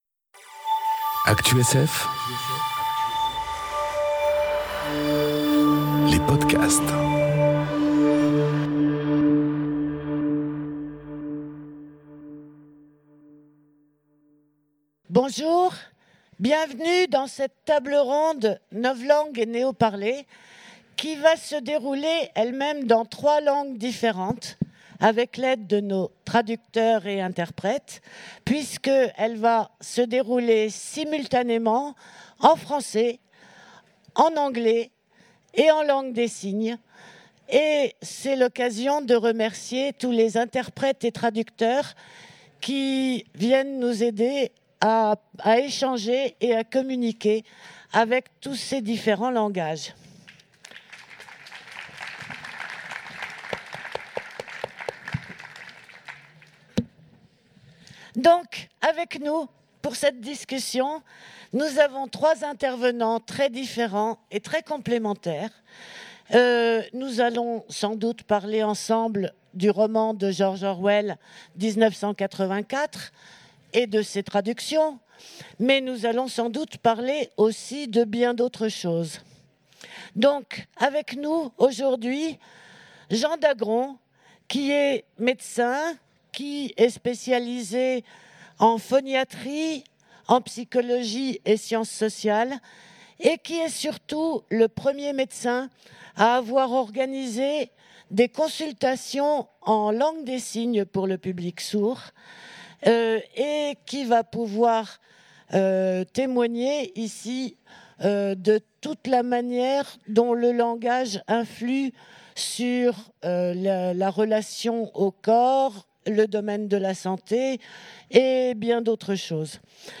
Conférence Novlangue et Néoparler enregistrée aux Utopiales 2018